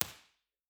Room Impulse Response of a large recording studio
Description:  Large recording studio (52,000 ft³) at university.
With a T30 of about 0.6 sec, this room is not statistically reverberant, but it is live and diffuse. There are some room modes below 200 Hz.
File Type: Mono
Source: 0.8 sec/octave log sweep.
IR_TP1_10ft_MedQ_Omni.wav